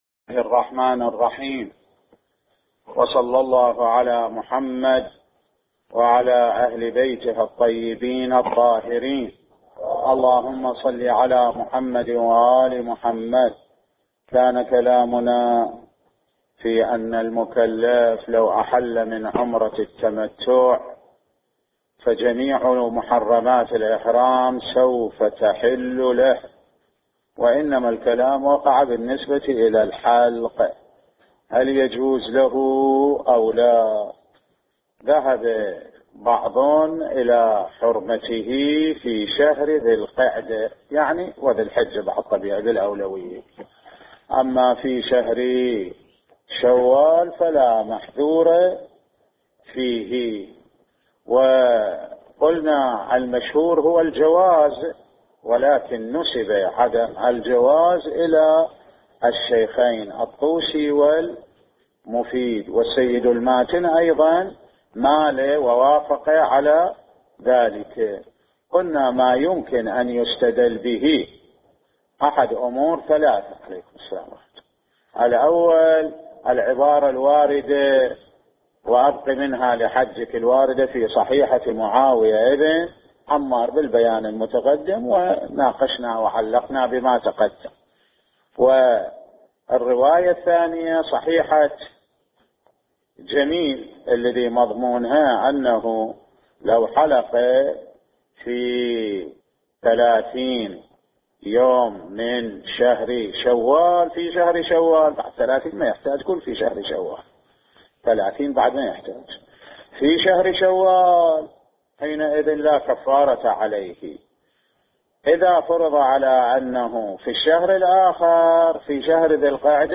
بحث الفقه